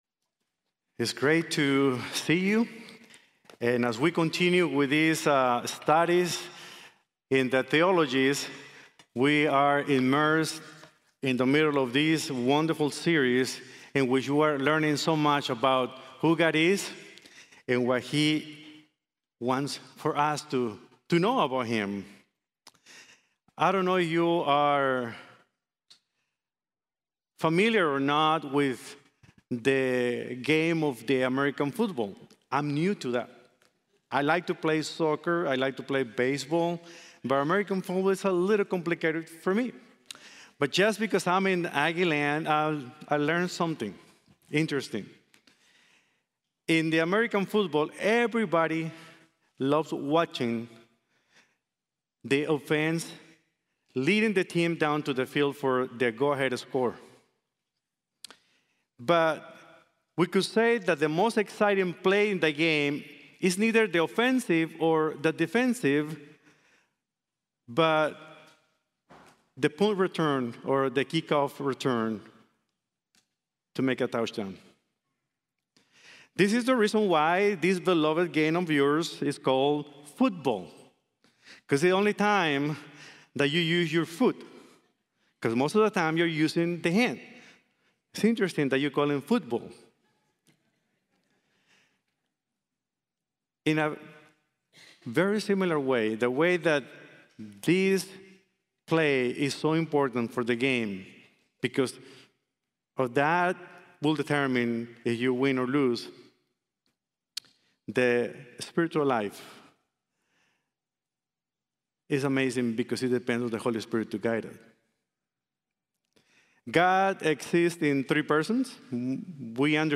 The Holy Spirit and I | Sermon | Grace Bible Church